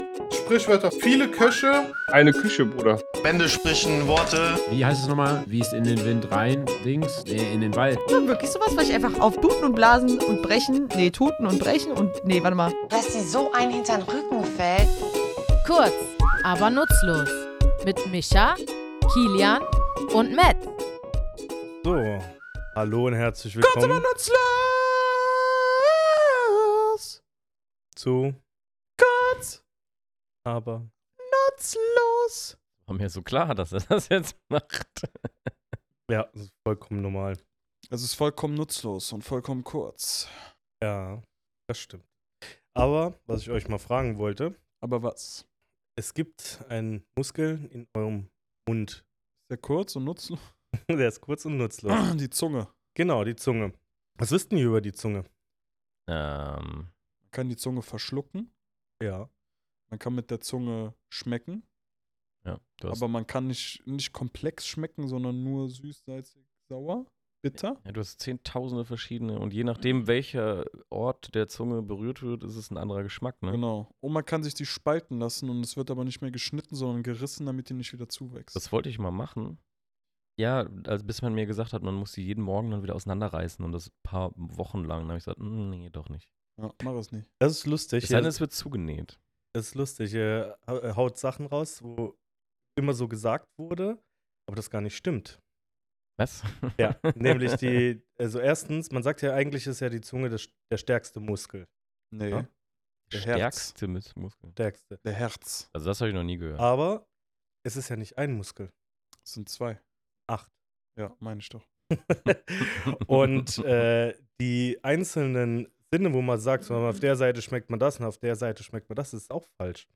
Wir, drei tätowierende Sprachliebhaber, schnacken in unserem Tattoostudio über die Herkunft, Bedeutung und die oft überraschenden Bilder, die in diesen Redensarten stecken.